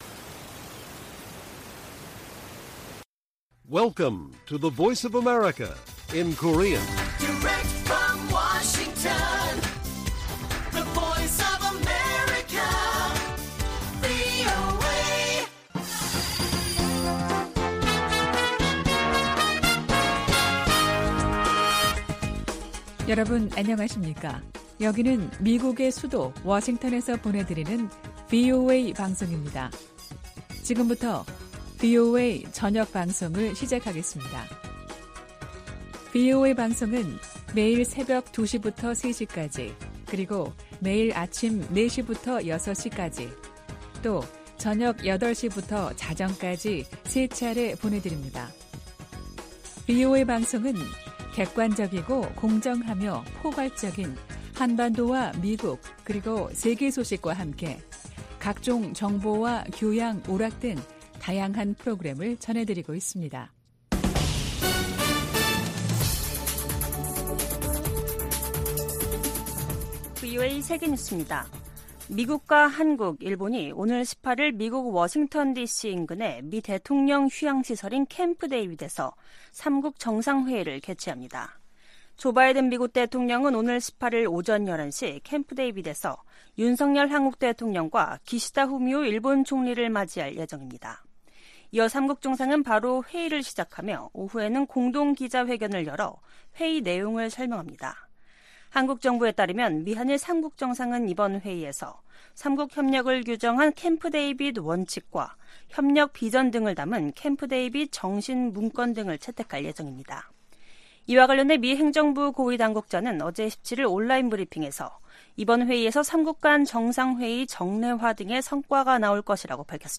VOA 한국어 간판 뉴스 프로그램 '뉴스 투데이', 2023년 8월 18일 1부 방송입니다. 미한일 캠프데이비드 정상회의에서 공동 안보 협약과 회의 정례화 등 역사적인 성과가 나올 것이라고 미국 고위당국자가 밝혔습니다. 6년 만에 열린 유엔 안보리 북한 인권 공개 논의에서 미국 등 52개국이 별도 성명을 발표했습니다. 미국 정부가 유엔 안보리에서 북한과 러시아 간 무기거래 문제를 제기하는 방안을 모색할 것이라고 유엔주재 미국대사가 밝혔습니다.